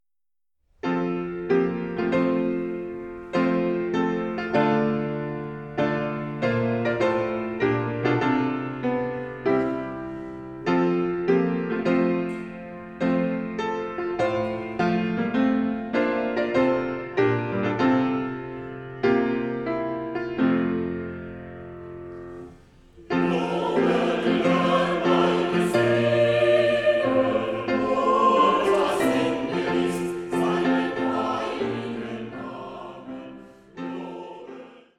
Streichensemble, Klavier